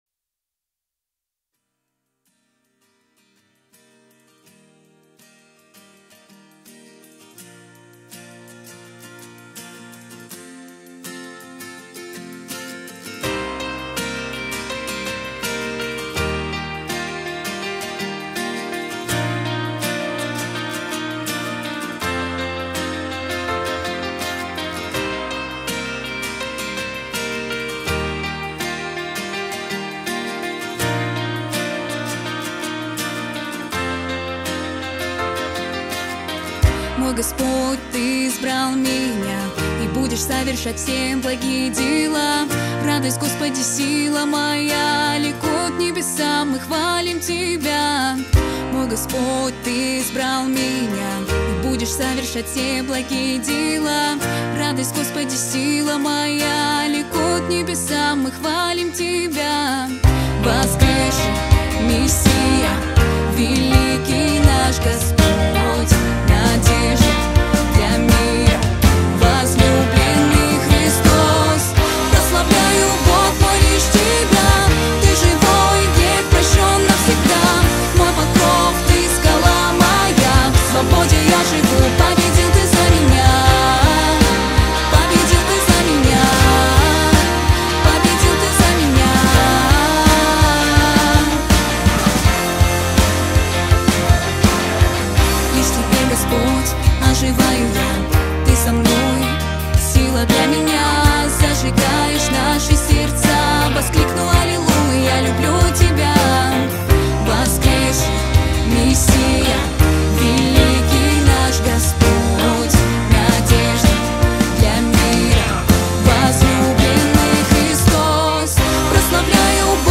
1192 просмотра 685 прослушиваний 43 скачивания BPM: 82